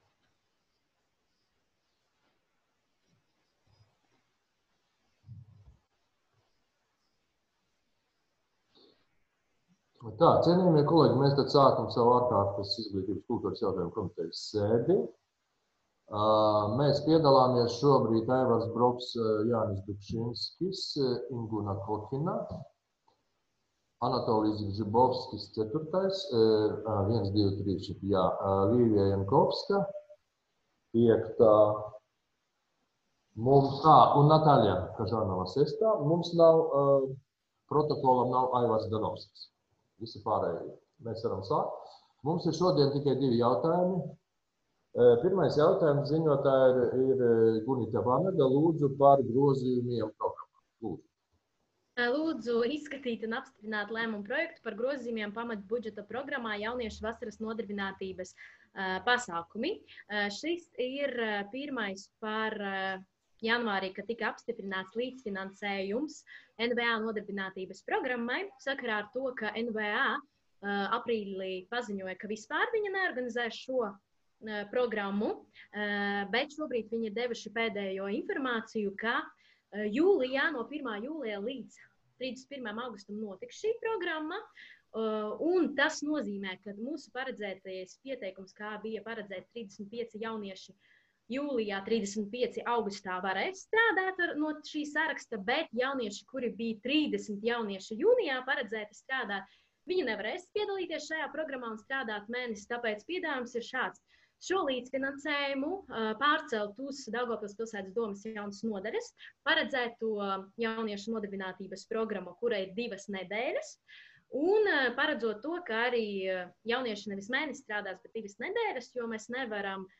Daugavpils pilsētas domes Izglītības un kultūras jautājumu komitejas priekšsēdētājs Aivars Broks sasauc ārkārtas Izglītības un kultūras jautājumu komitejas sēdi 2020.gada 28.maijā, plkst.13.30 Videokonferences režīmā un izsludina šādu darba kārtību: (AUDIO)